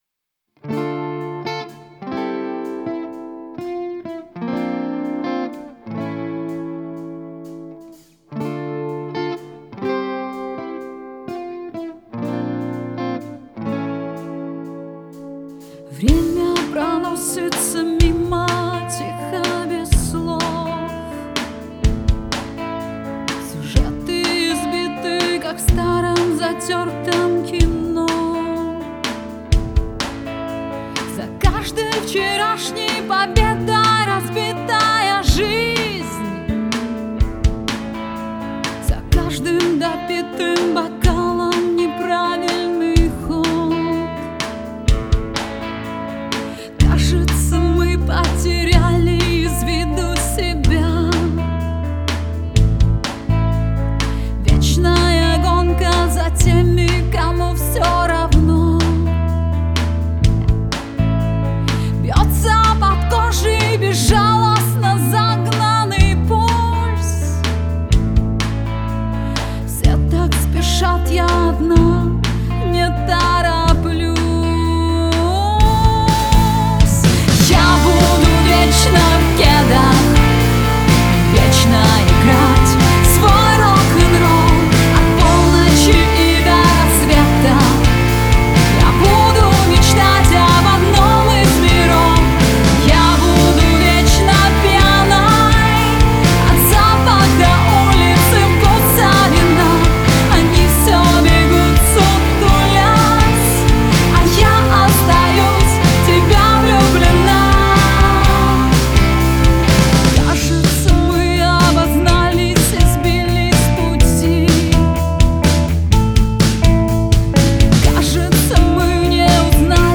• Жанр: Рок, Русская музыка, Русский рок